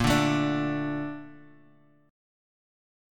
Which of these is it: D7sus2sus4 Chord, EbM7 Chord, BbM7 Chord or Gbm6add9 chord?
BbM7 Chord